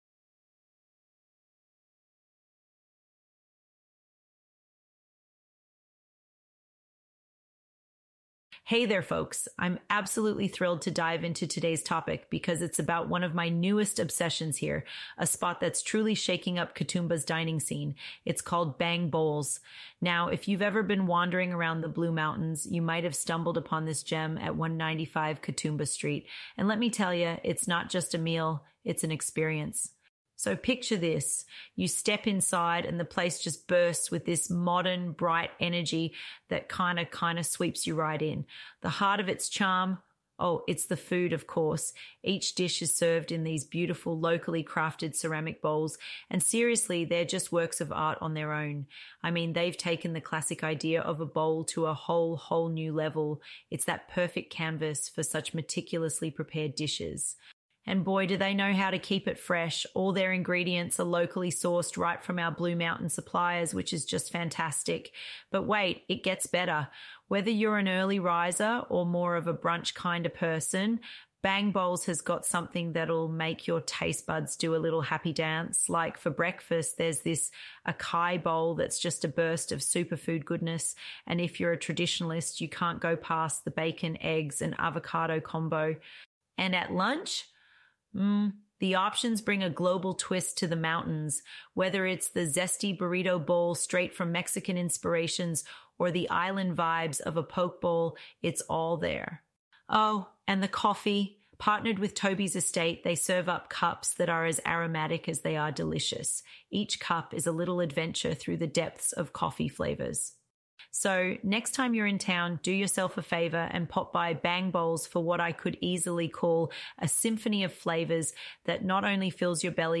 This podcast was computer generated